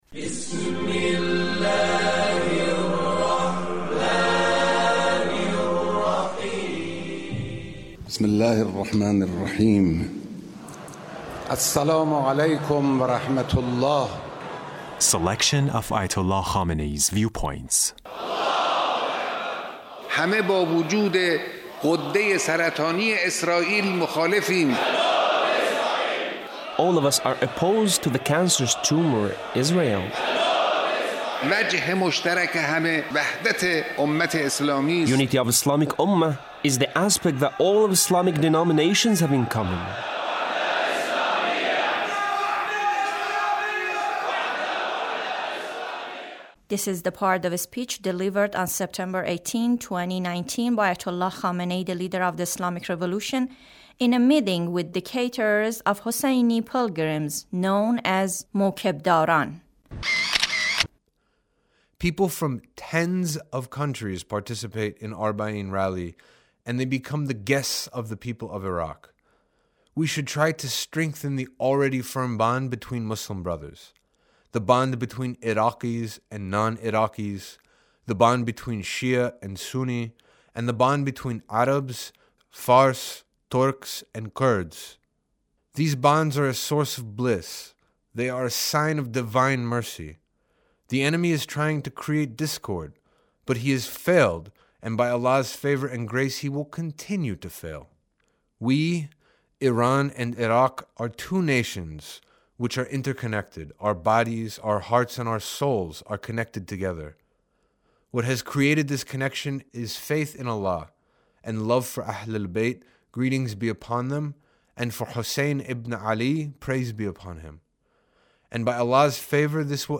Leader's Speech (18)
The Leader's speech on "ARBAEEN"